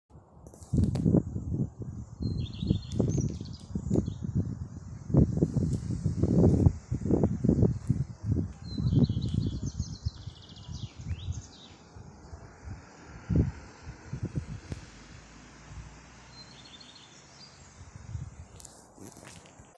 горихвостка-лысушка, Phoenicurus phoenicurus
Ziņotāja saglabāts vietas nosaukumsRoja
СтатусПоёт